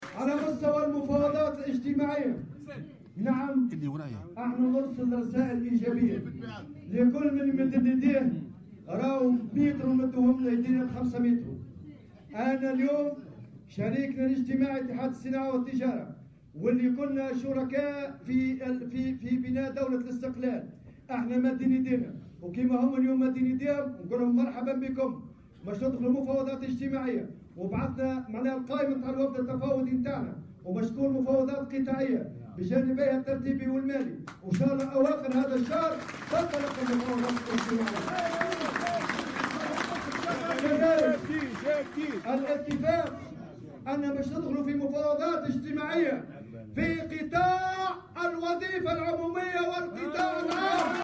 وأضاف خلال اجتماعي عمالي اليوم الأحد في بنزرت، أنه سيتم كذلك الدخول في مفاوضات في القطاع العام والوظيفة العمومية.